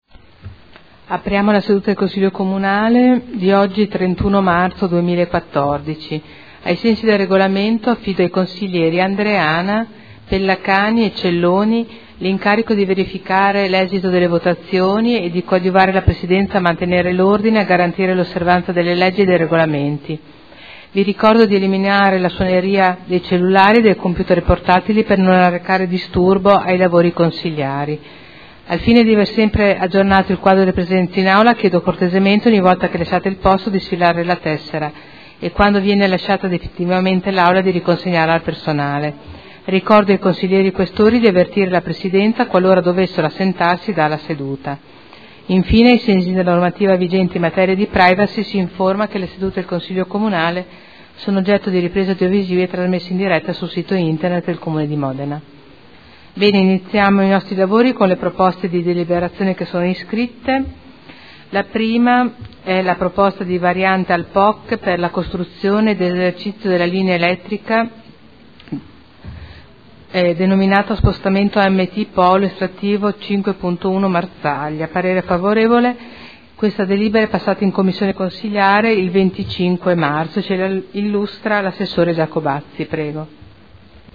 Seduta del 31 marzo. Apertura del Consiglio Comunale